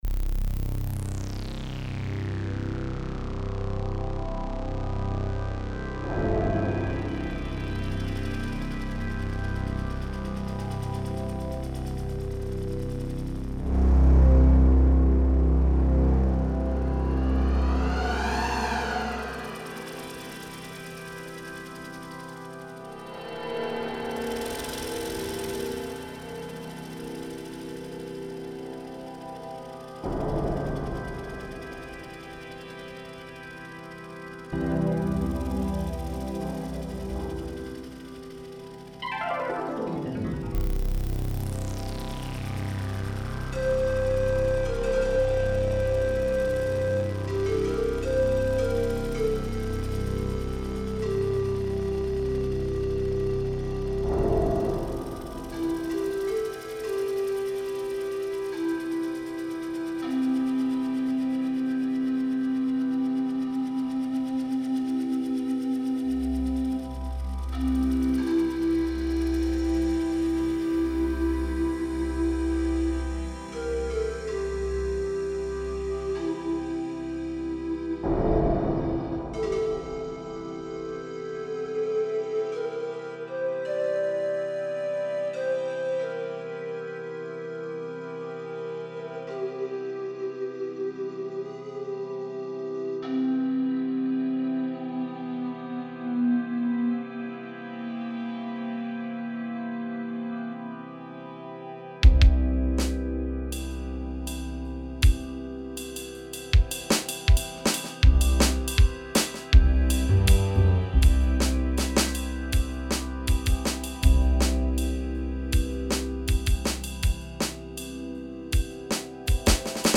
Det är drum 'n' bass. 8 minuter och 15Mb at ladda ned. 256kbit/s. Crest 20,7 så ni får vrida upp volymen, medlenivån ligger förhållandevis lågt.
Hela Mopho-tracket är improviserat med diverse skruvande på frekvens, rosnans och envelopen till filtret.
På slutet var det lite djupa bastoner, men överlag tyckte jag inte att det var avgrundsdjupt.
Nej, det är på slutet det skall gå ned riktigt djupt.
dnb-1.mp3